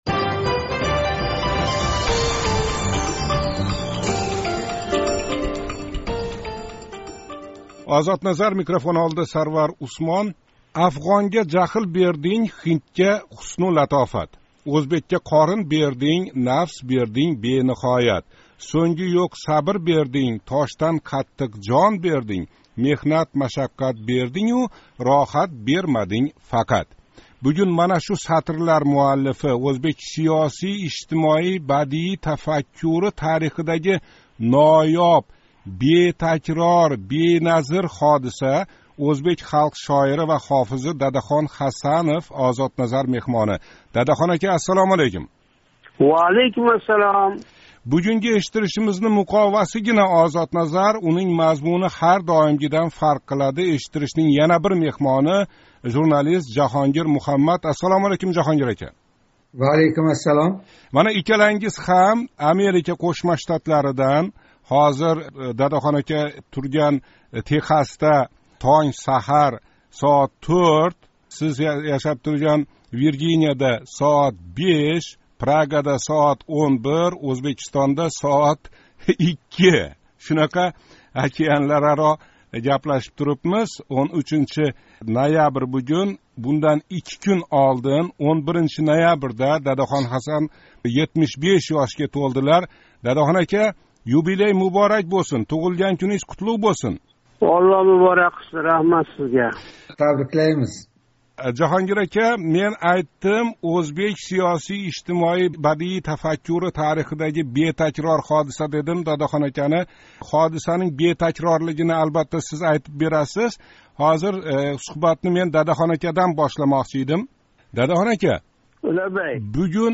OzodNazar юбилярни меҳмонга чорлади ва табриклаш баҳона у кишини суҳбатга тортди. Дадахон Ҳасанов Ислом Каримов билан нималарни гаплашган эди? Яқинда Техасда учрашганида Дадахон акага Абдулла Орипов нималар деди?